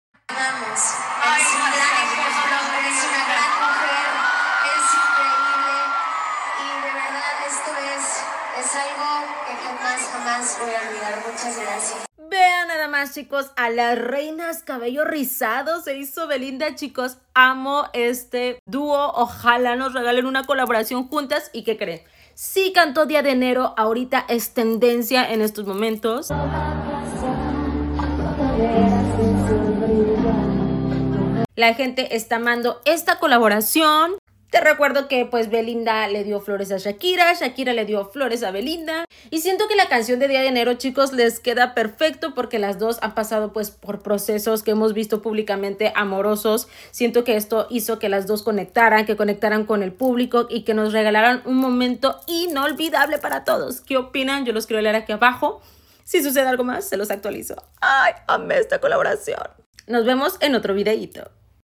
en Ciudad de México